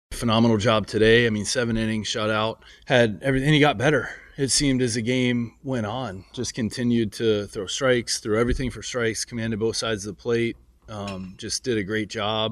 Keller’s seven strikeouts gives him 389 K’s at PNC Park, passing Paul Maholm’s record of 383.  Manager Don Kelly says Keller got stronger as the game went on, outdueling Cardinals starter Sonny Gray.